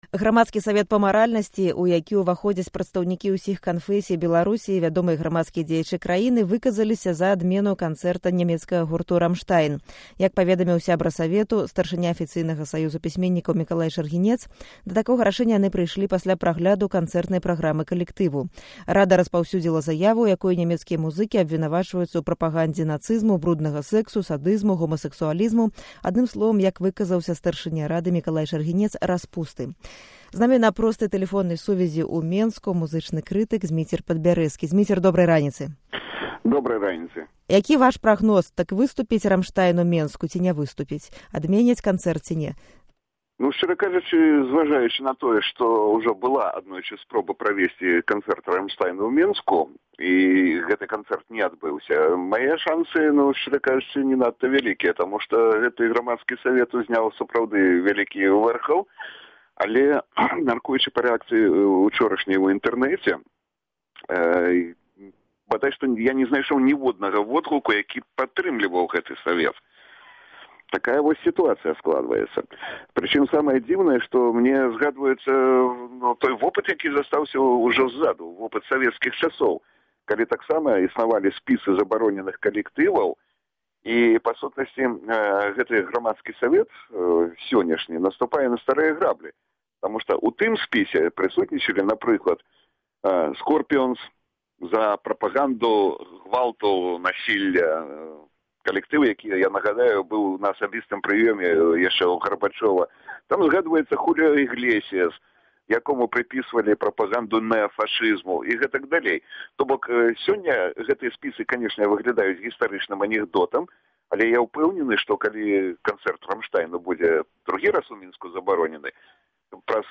Гутарка